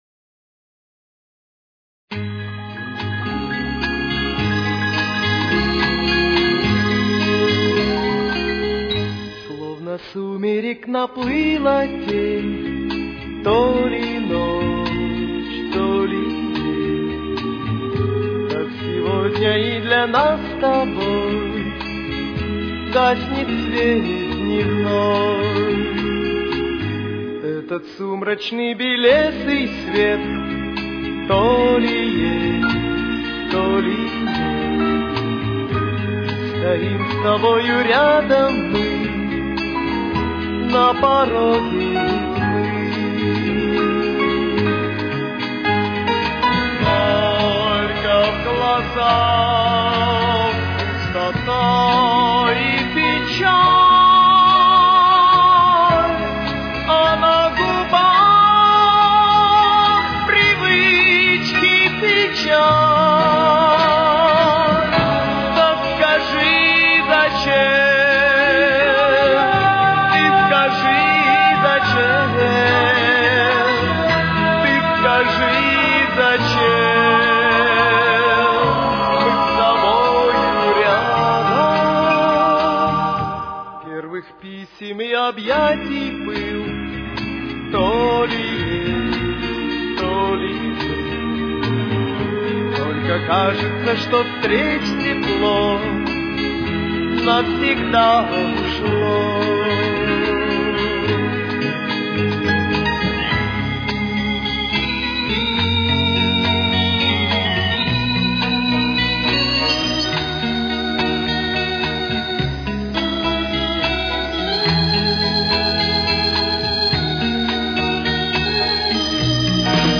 с очень низким качеством